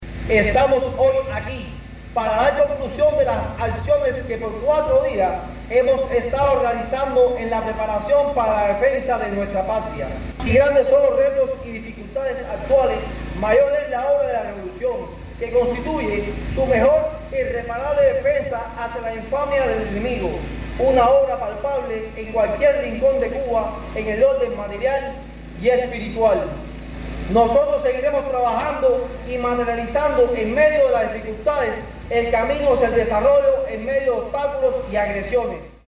PEDRO BETANCOURT.- Un acto político-cultural de reafirmación patriótica y respaldo a la Revolución y sus conquistas socialistas acogió el Consejo Voluntario Deportivo Jaime López Secada, de este municipio, como parte de las actividades en saludo al Día Nacional de la Defensa.
Las palabras finales estuvieron a cargo de Royslán Cámbara Sosa, presidente del Consejo de Defensa municipal, quien subrayó la necesidad de esta iniciativa para continuar perpetuando bajo la concepción de la Guerra de Todo el Pueblo, la seguridad y soberanía de la patria.